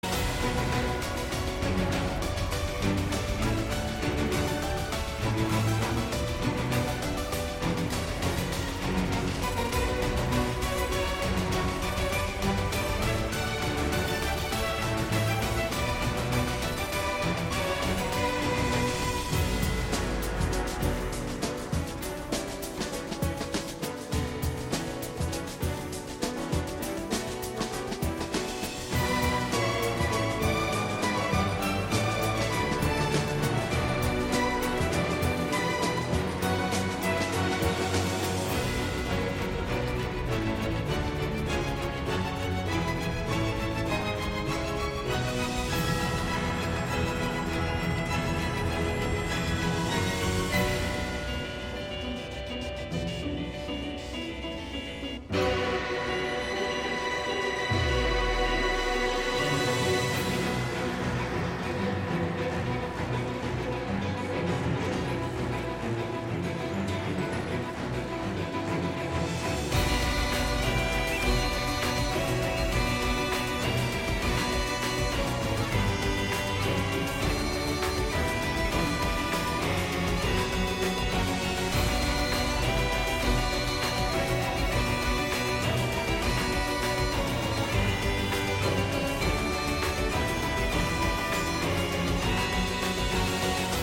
あと悪魔城あじを感じる戦闘BGMなので置いておくでやんす